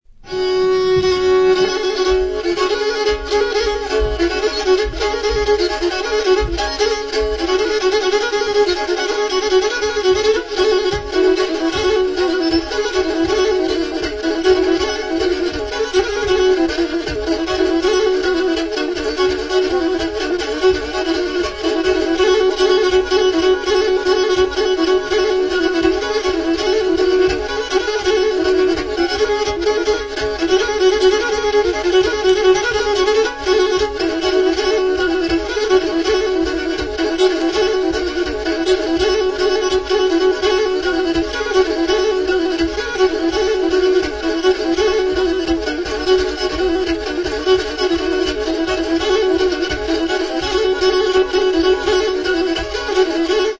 PONTIC LIRA / KEMENTZE; LYRA; KEMENÇE | Soinuenea Herri Musikaren Txokoa
Metalezko hiru soka ditu. Sokak igurtzitzeko zurezko arkua, zurdazko sokarekin.